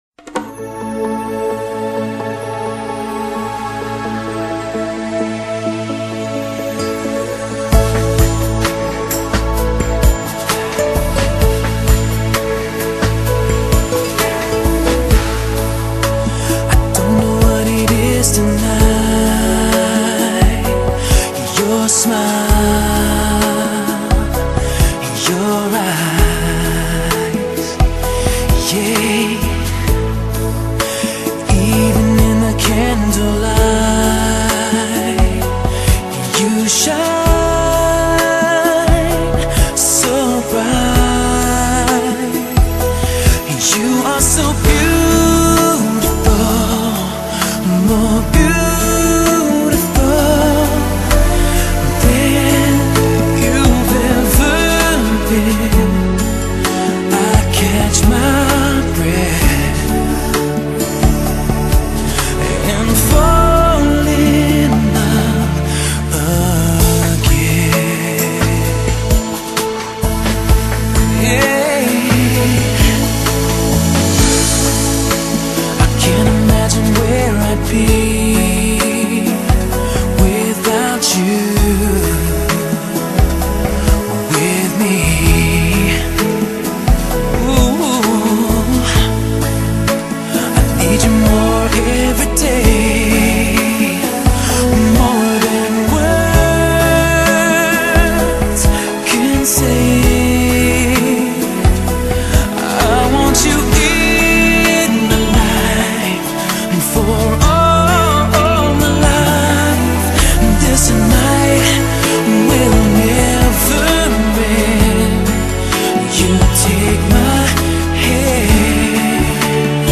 风格类型：Rock-Pop